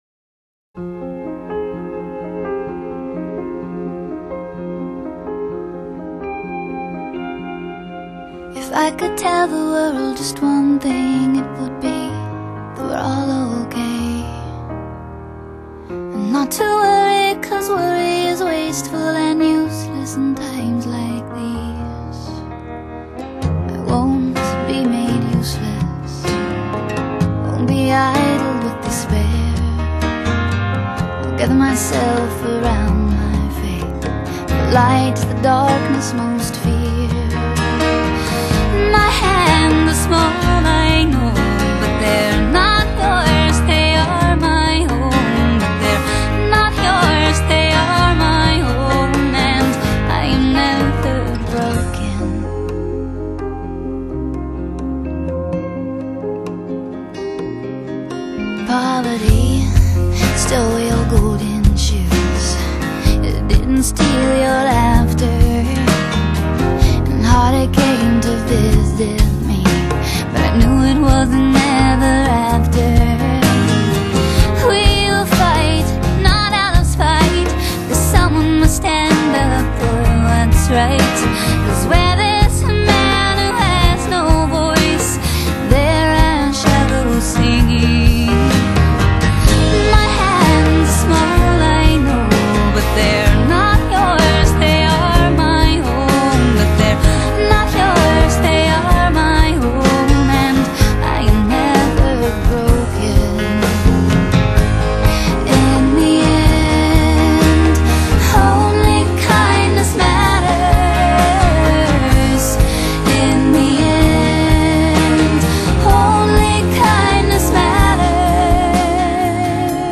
不慍不火的摇滚和乡村，偶而渗透到其中的几首作品当中，风格统一且概念完整表达。